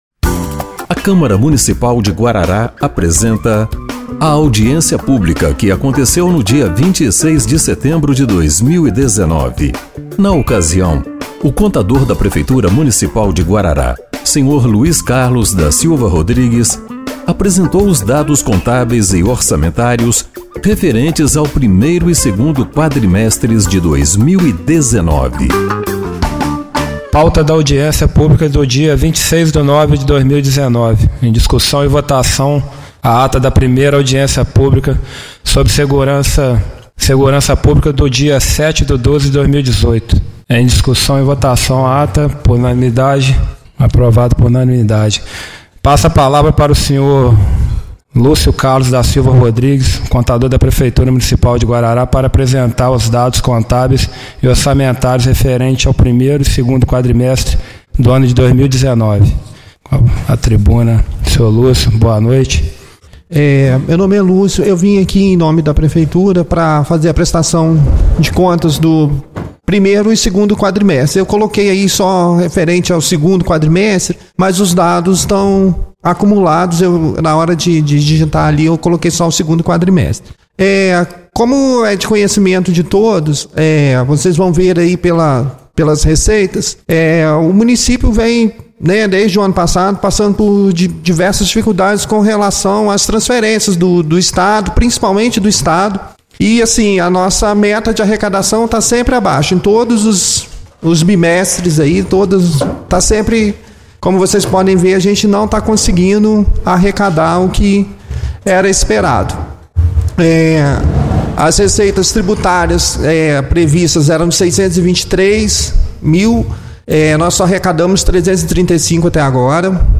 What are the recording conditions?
Audiência Pública de 26/09/2019